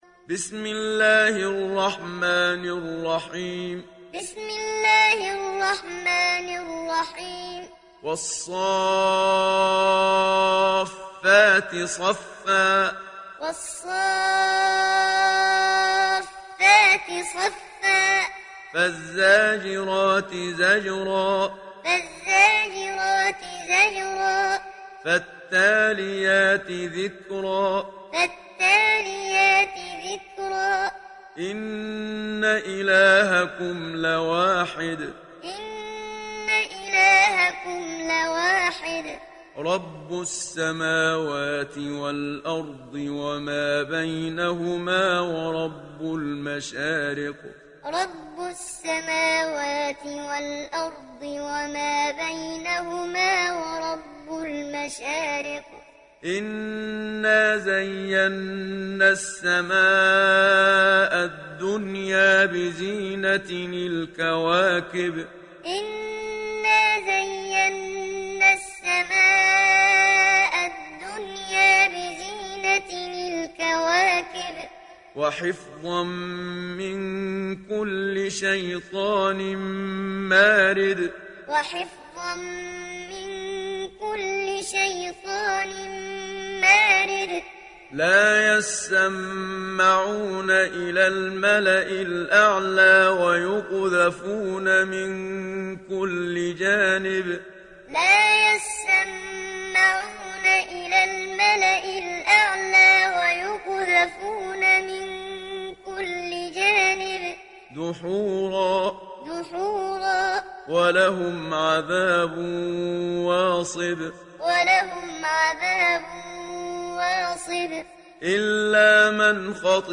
دانلود سوره الصافات محمد صديق المنشاوي معلم